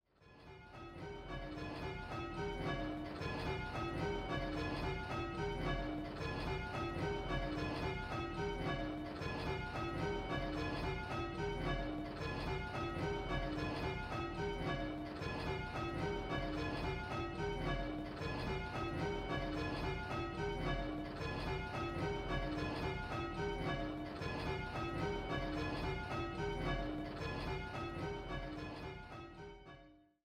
5 & 6 Bell Game